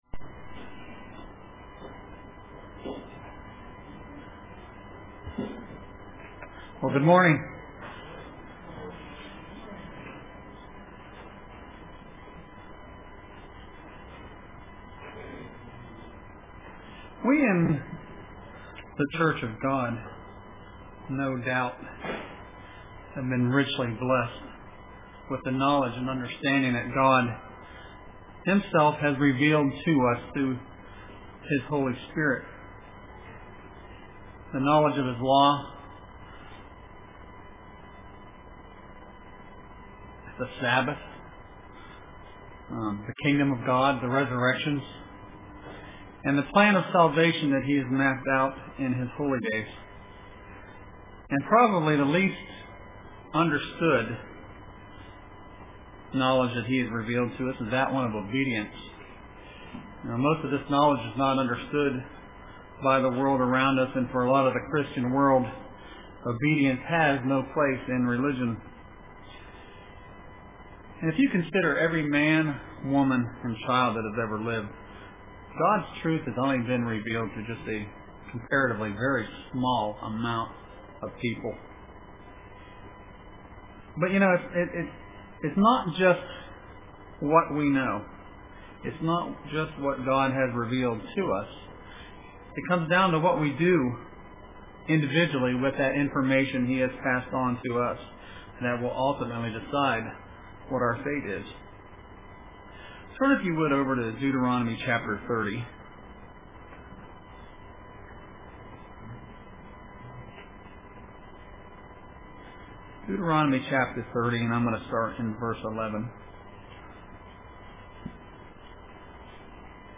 Print Remember Lot's wife Choose Life UCG Sermon Studying the bible?